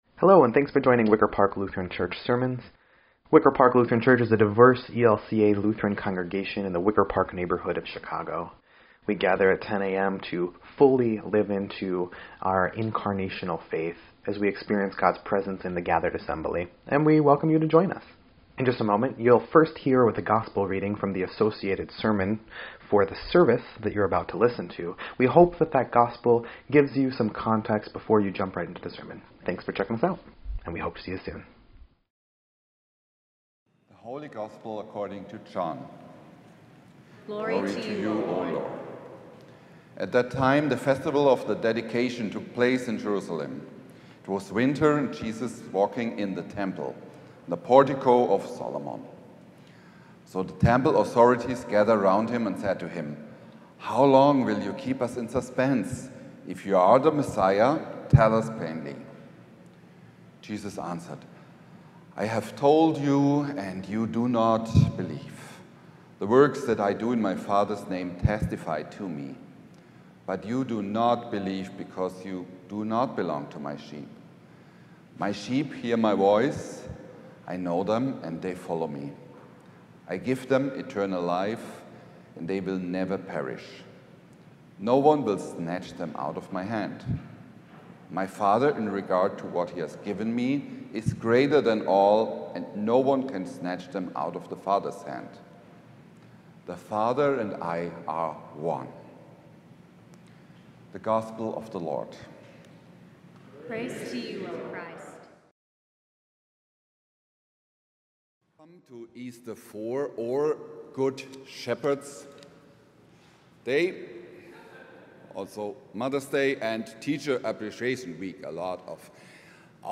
5.11.25-Sermon_EDIT.mp3